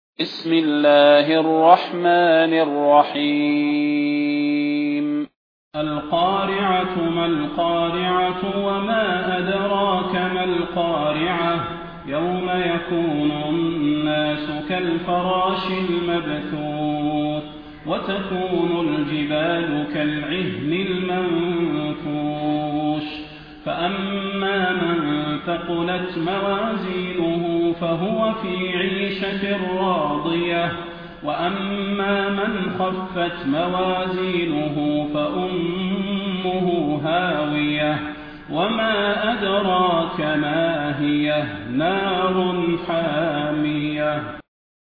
المكان: المسجد النبوي الشيخ: فضيلة الشيخ د. صلاح بن محمد البدير فضيلة الشيخ د. صلاح بن محمد البدير القارعة